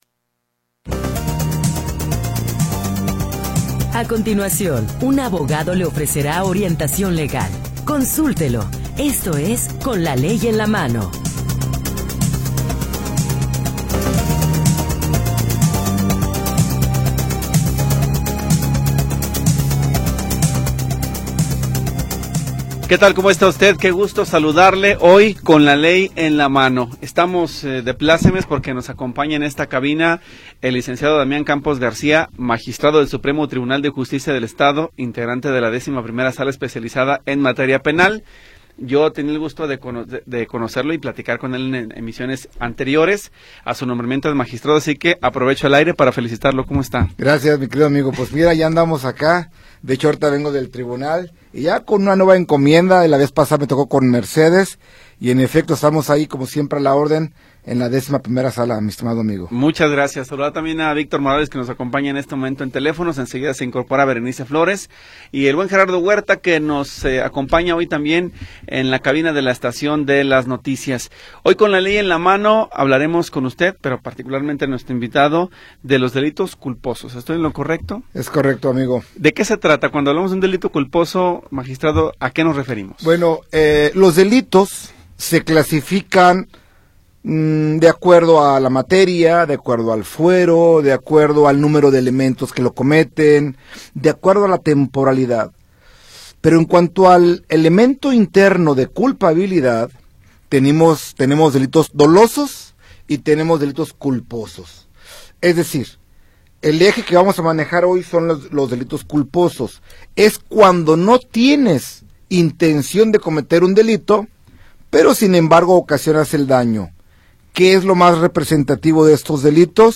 Orientación legal de jueces y abogados especialistas